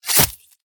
general / combat / weapons / sb1 / flesh3.ogg
flesh3.ogg